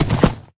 gallop1.ogg